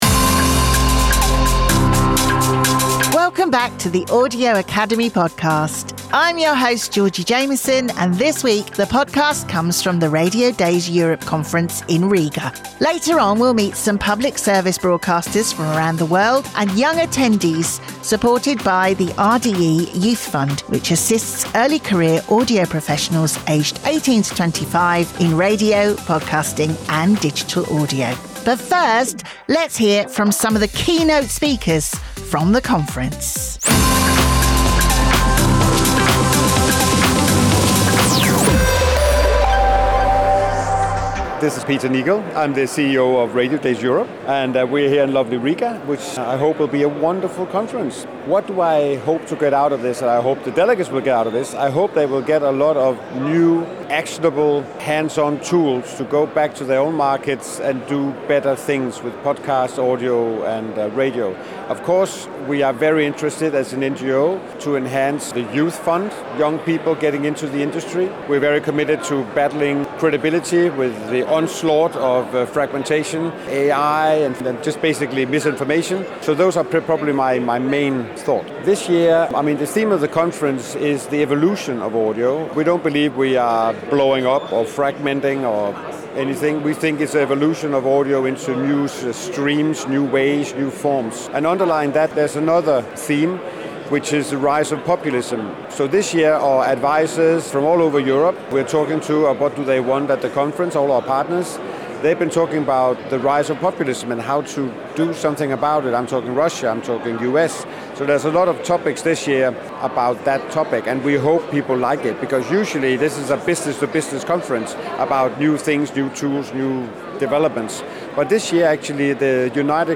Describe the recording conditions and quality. This week, the podcast was recorded at Radiodays Europe in Riga.